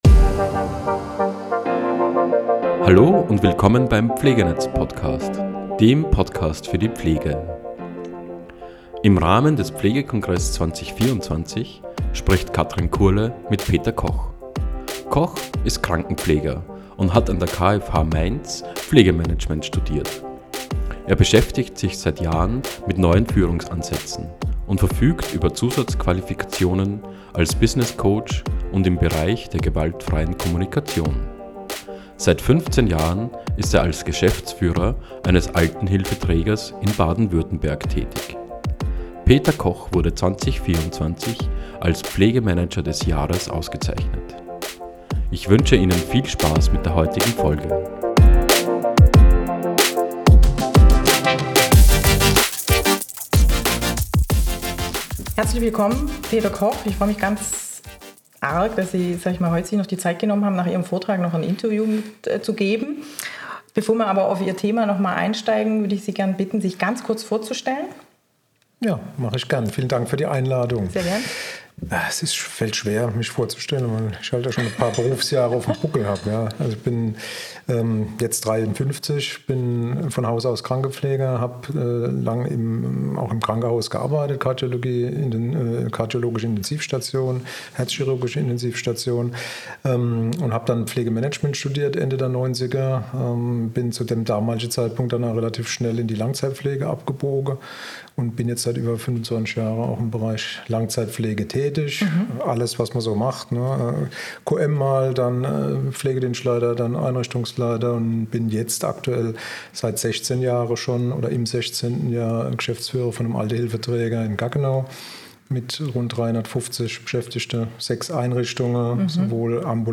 Pflegekongress24